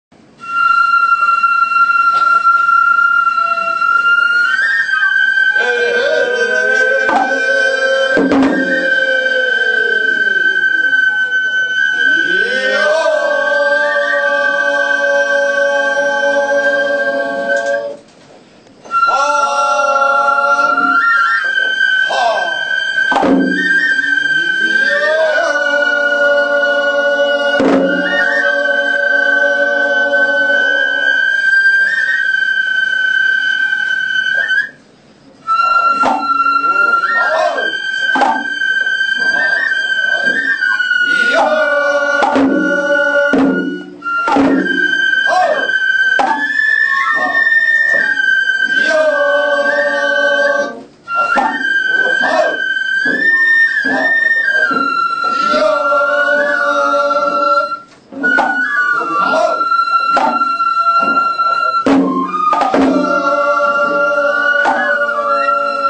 尾張の山車囃子～横須賀
からくり人形演技で演奏される。